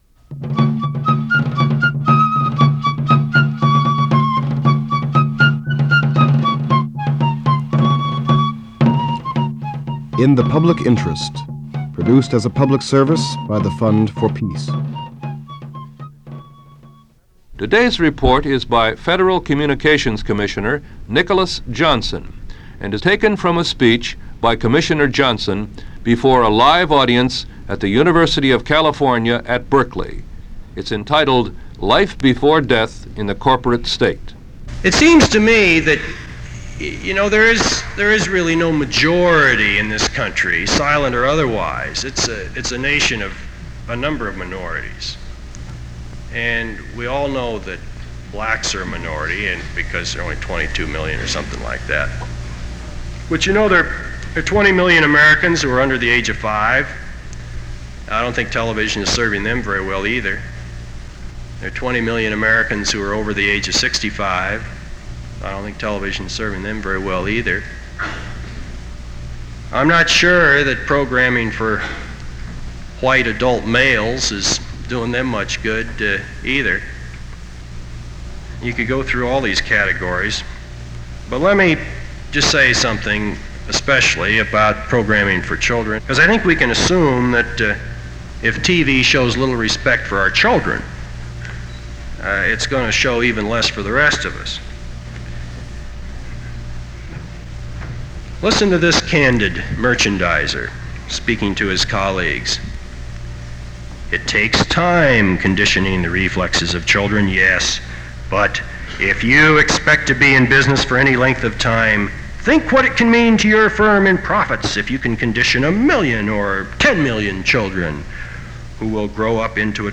This is the second of two files in which “In the Public Interest” ran an excerpt from Nicholas Johnson’s speech, “Life Before Death in the Corporate State,” University of California, Berkeley, November 5, 1970.